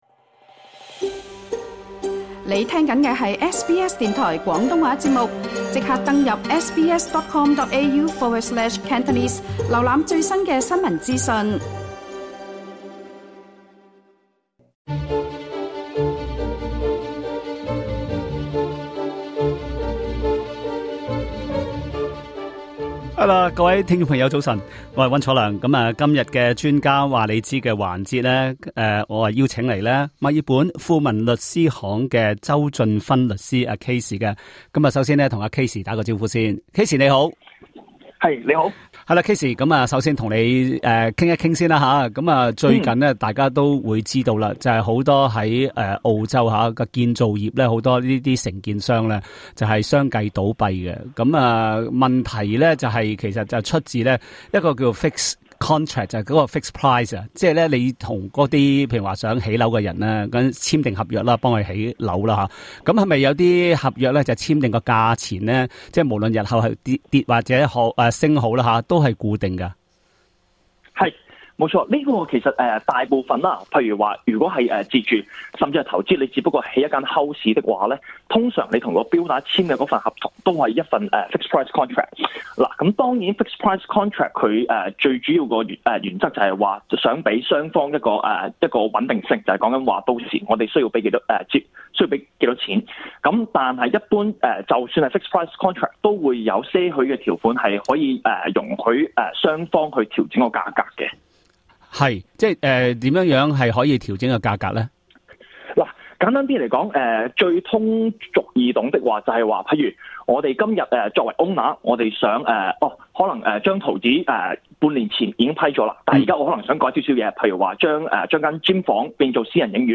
他在這節「專家話你知」中，還解答了聽眾於不同方面的查詢。